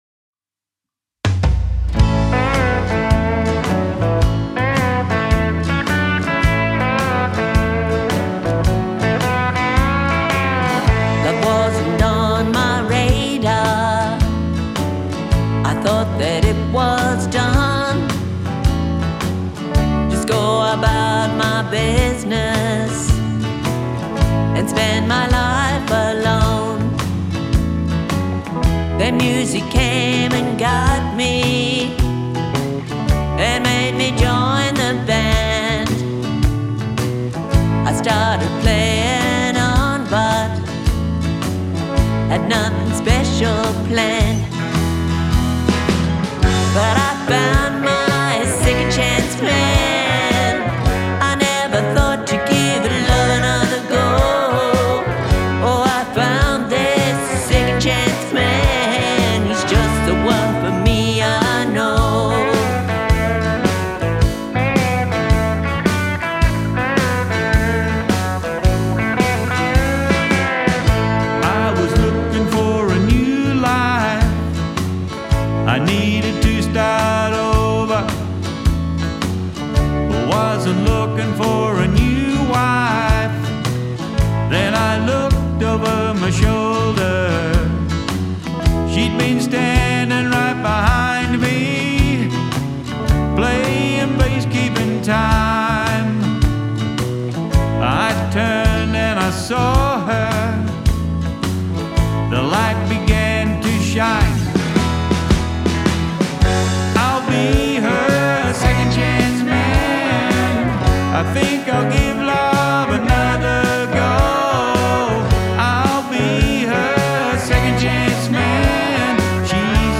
It’s really a love song but also a promise of commitment.